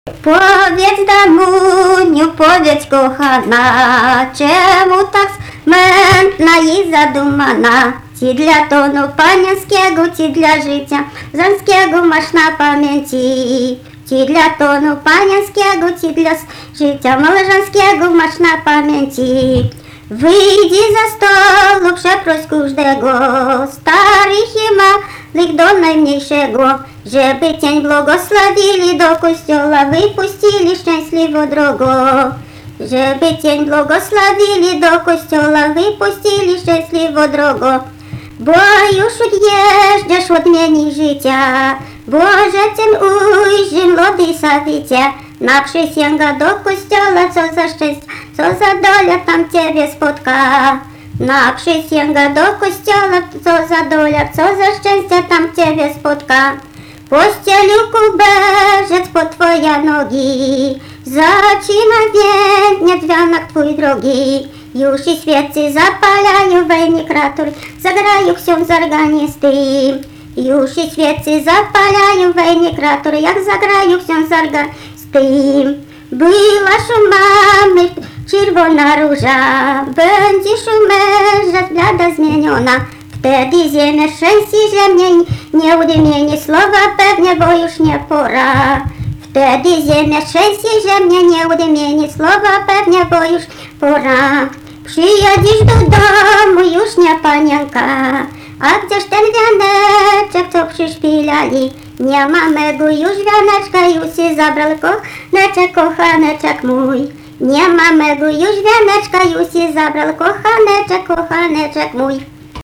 vokalinis